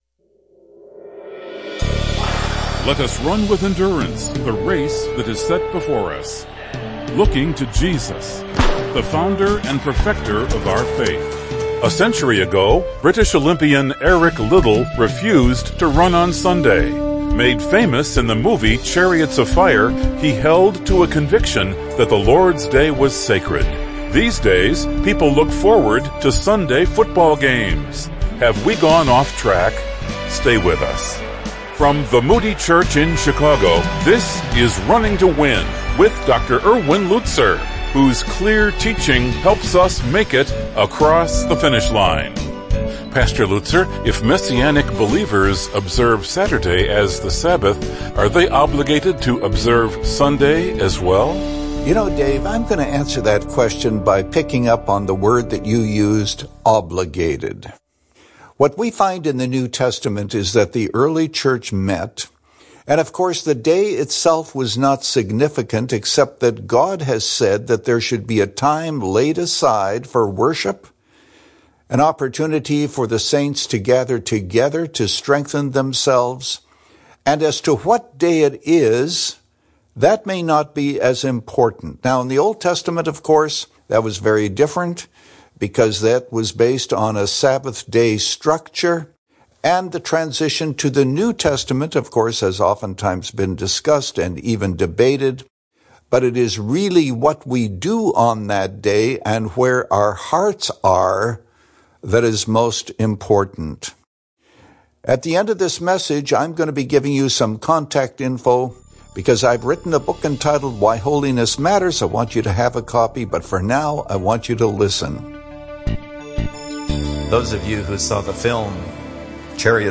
In this message, Pastor Lutzer highlights two essential physical and spiritual aspects of the Sabbath day. But for New Testament believers, do the Scriptures reflect the shift from Sabbath to Sundays?